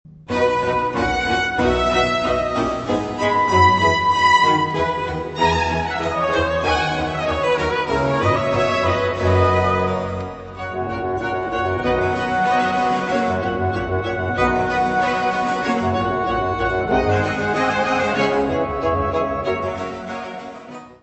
: stereo; 12 cm
soprano
contralto
baixo
Área:  Música Clássica
Aria